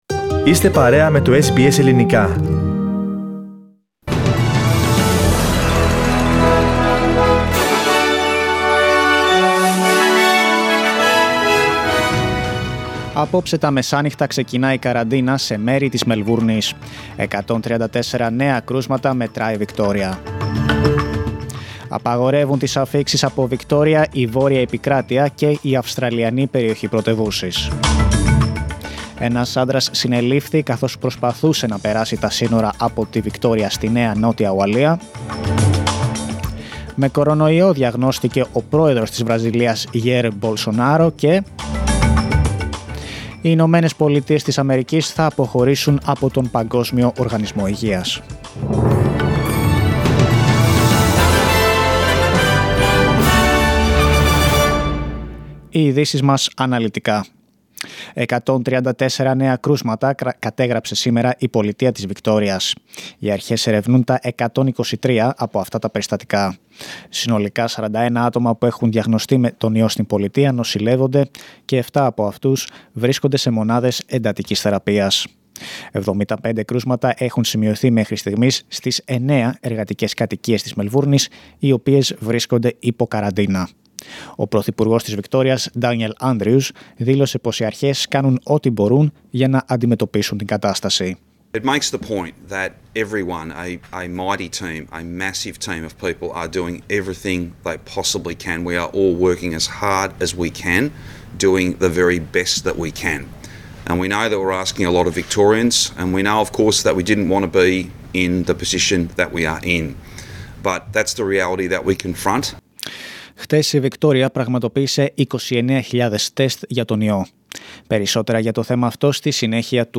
News in Greek from Australia, Greece, Cyprus and the world, in the News Bulletin of Wednesday 8th of July.